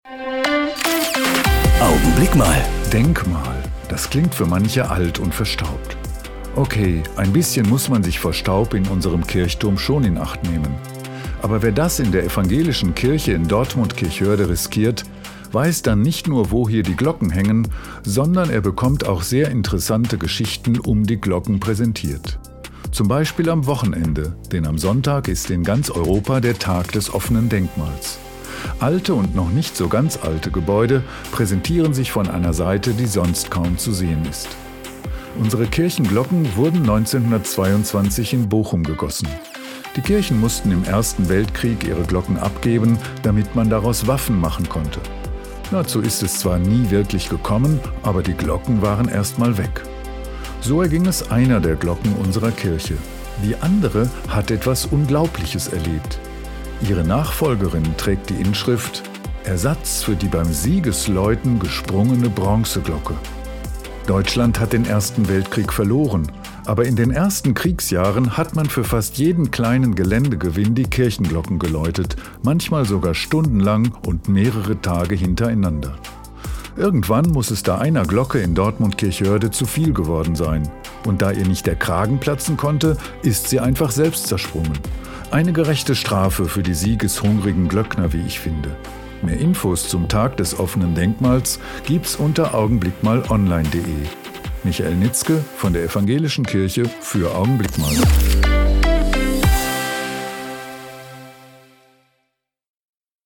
Radioandachten